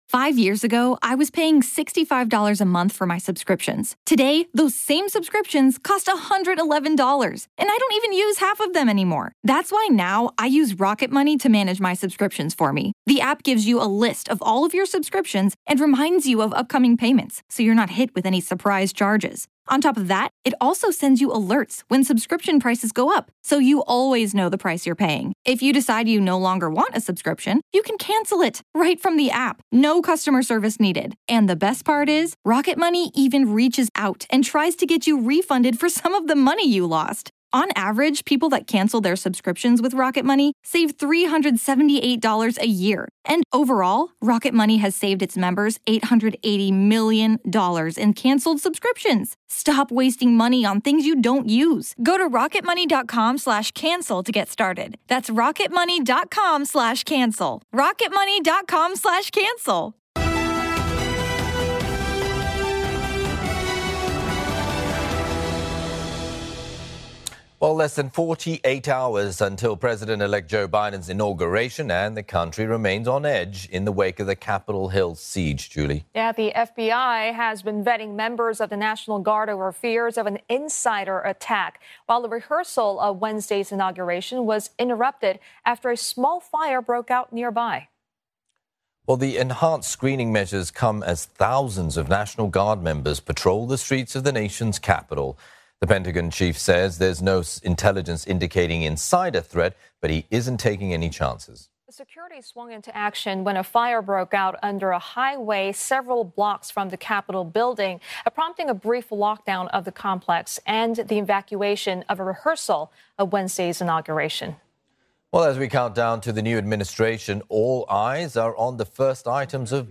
live report